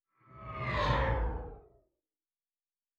pgs/Assets/Audio/Sci-Fi Sounds/Movement/Distant Ship Pass By 3_1.wav at 7452e70b8c5ad2f7daae623e1a952eb18c9caab4
Distant Ship Pass By 3_1.wav